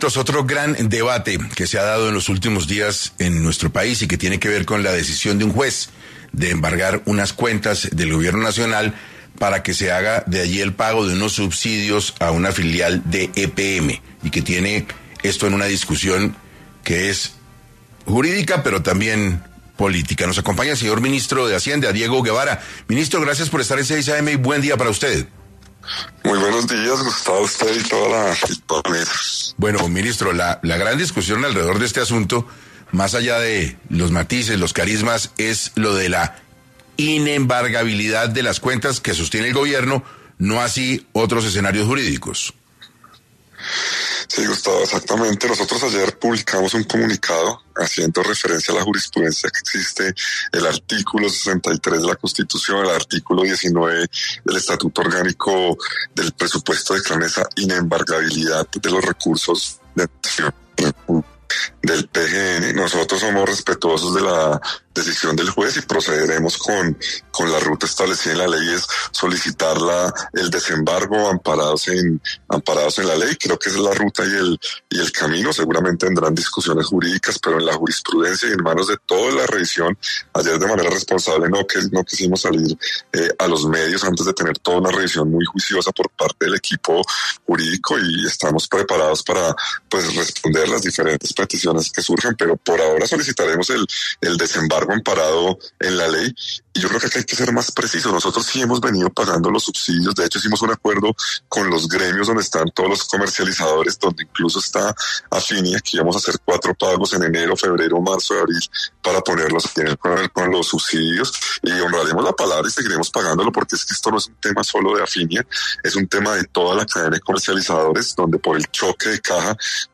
El ministro de Hacienda, Diego Guevara, confirmó en 6AM de Caracol Radio que el Gobierno Nacional está evaluando un nuevo recorte presupuestal, adicional a los 12 y 10 billones de pesos ya anunciados.